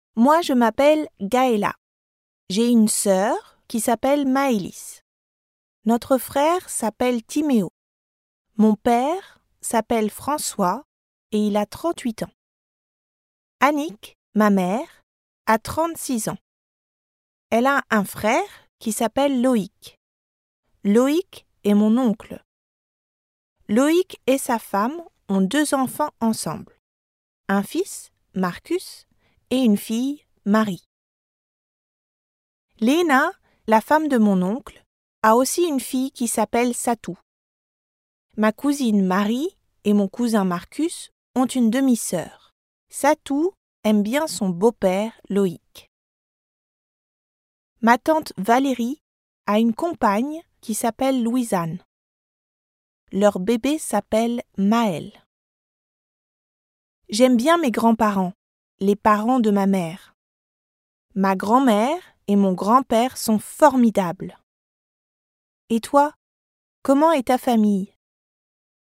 Synchronsprecherin für Deutsch mit französischem Akzent Native Speaker für französische Synchronisation, französische Voice Over, französische Regie, Übersetzungen und Dialogbücher
Sprechprobe: Sonstiges (Muttersprache):
dubbing actress in german with french accent and native french speaker, director, translator and writer.